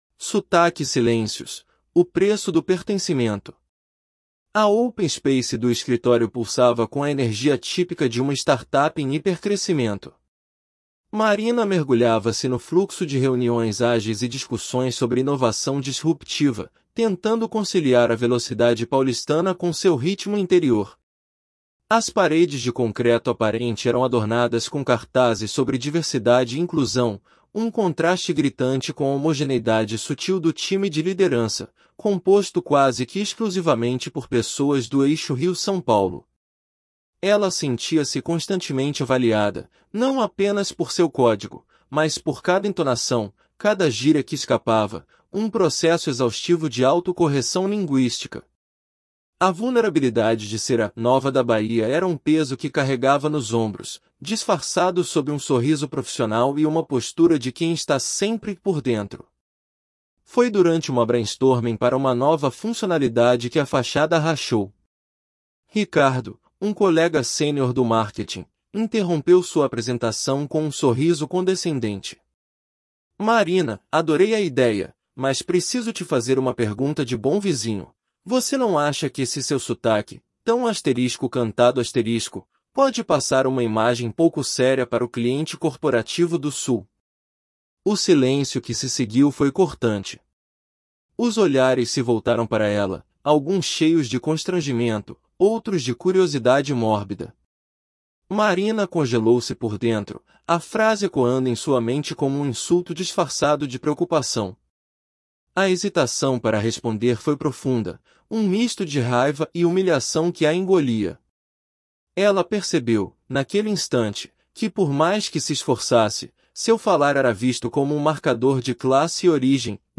• Before reading: Listen to understand rhythm, intonation, and natural speech.